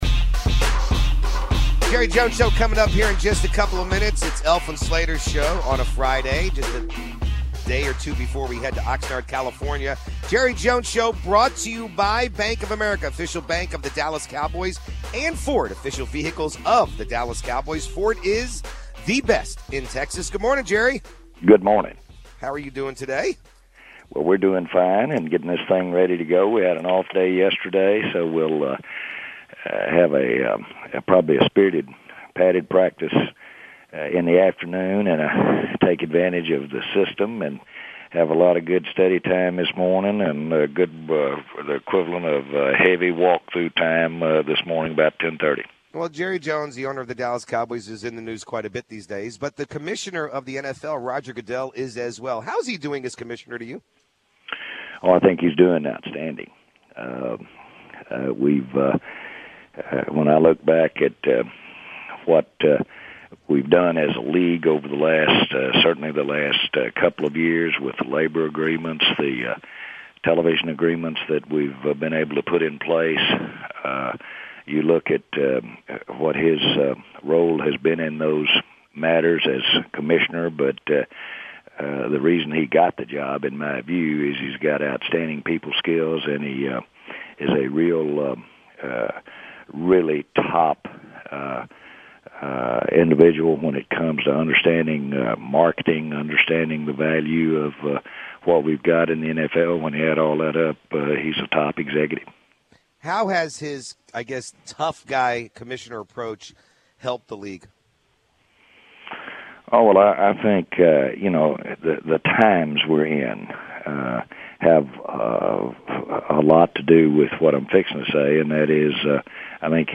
Live from Cowboys Camp in Oxnard, CA